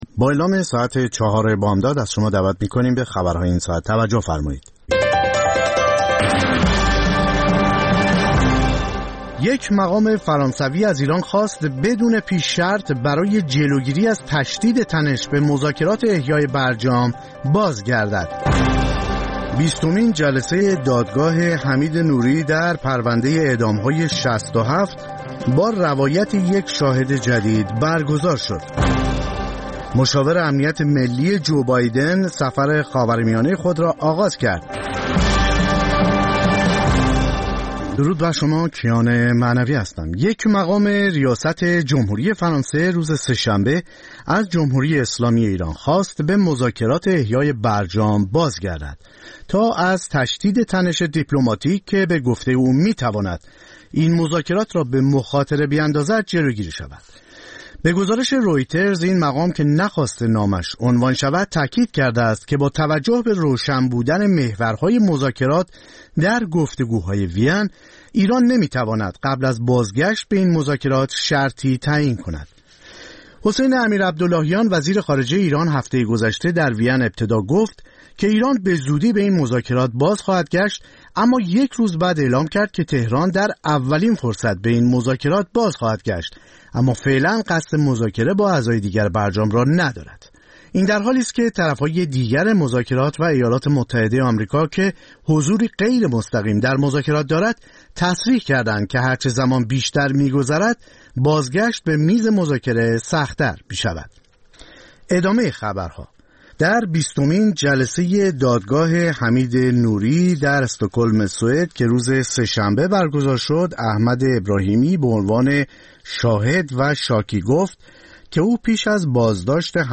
سرخط خبرها ۴:۰۰